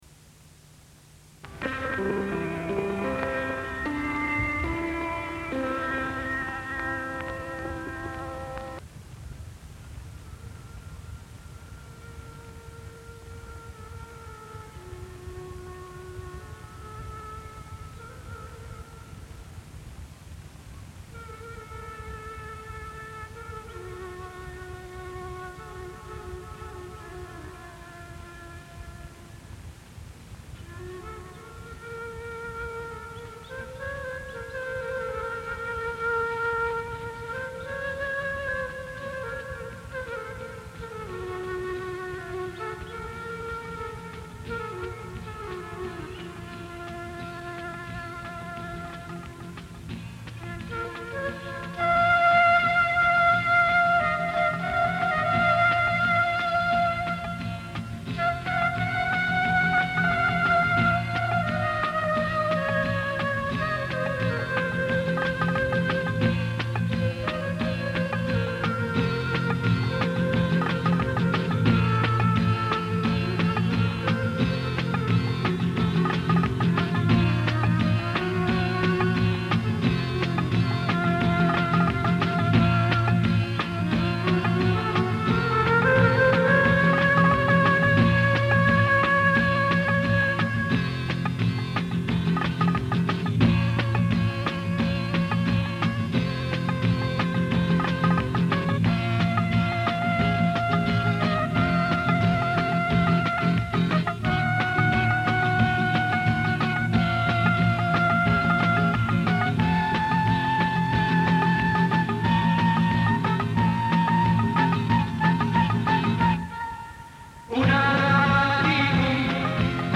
Un fichier MP3 correspondant à une face de cassette audio.